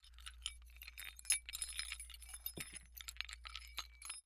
Metal_48.wav